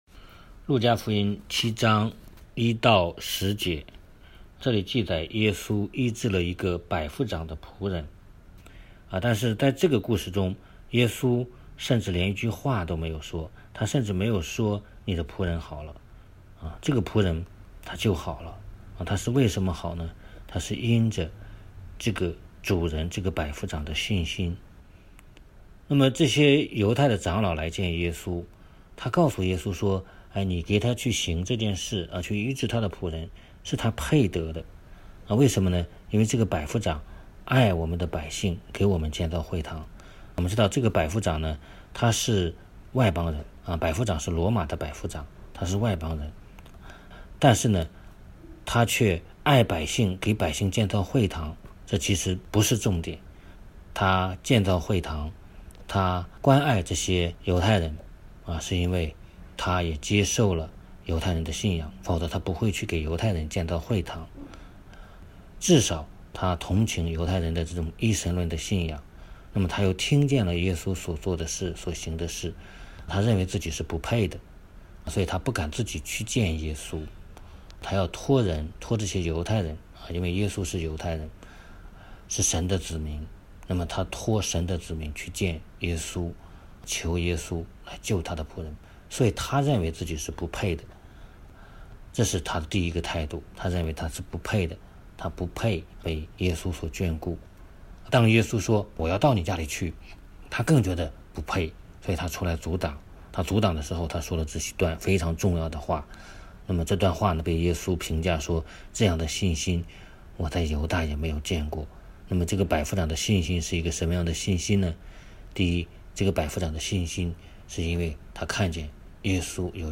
2025 聖經學習 https